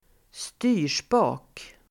Uttal: [²st'y:r_spa:k]